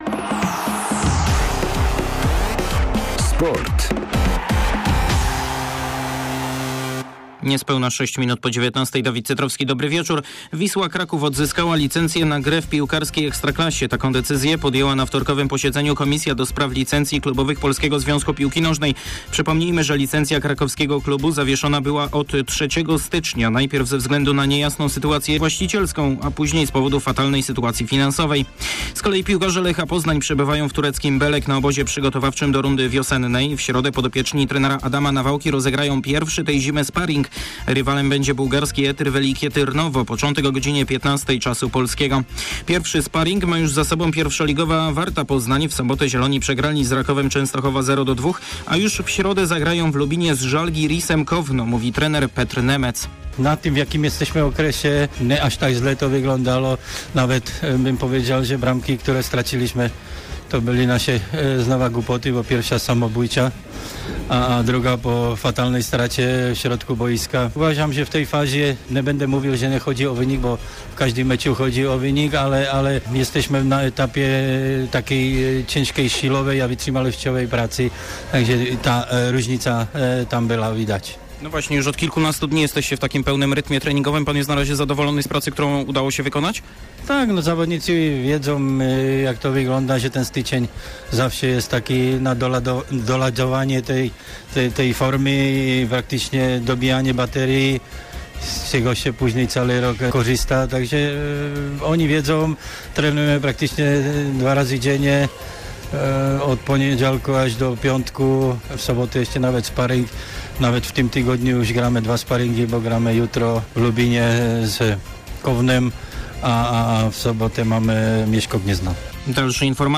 22.01. serwis sportowy godz. 19:05